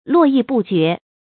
注音：ㄌㄨㄛˋ ㄧˋ ㄅㄨˋ ㄐㄩㄝˊ
絡繹不絕的讀法